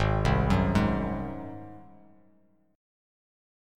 Listen to G#7 strummed